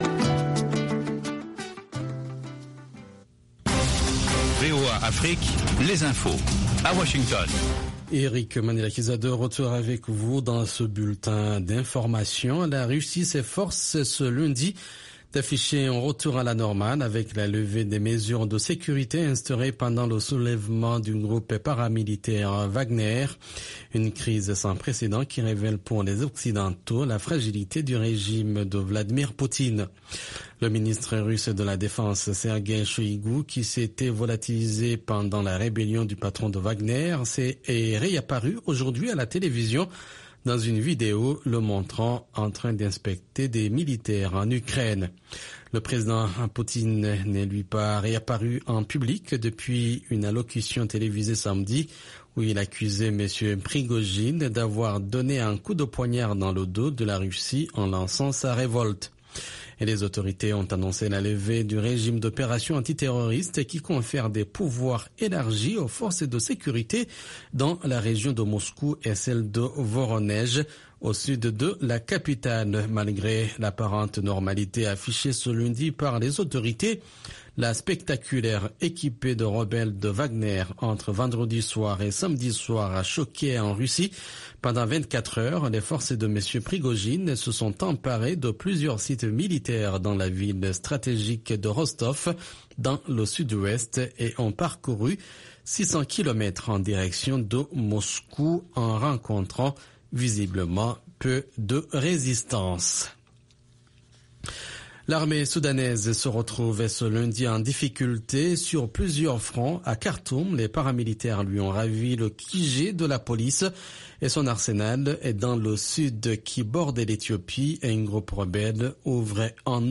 Bulletin d’information de 13 heures